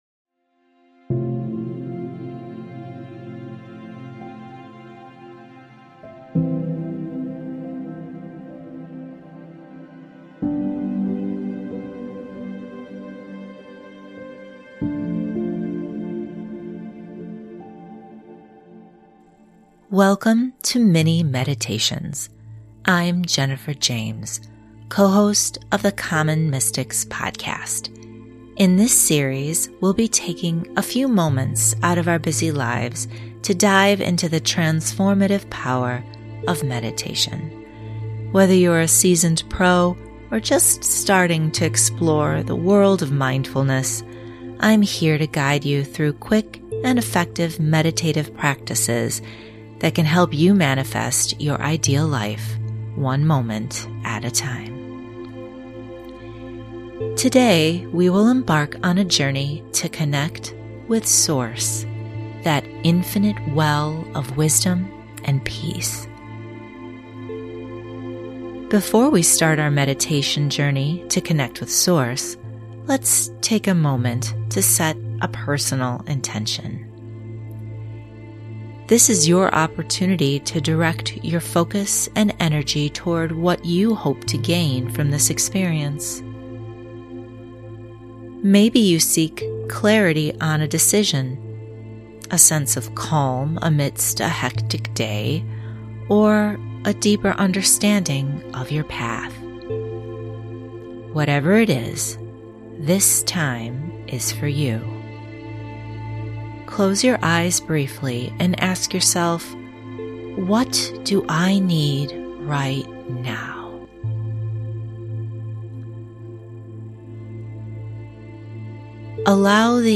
Welcome to Common Mystics' Mini Meditation series!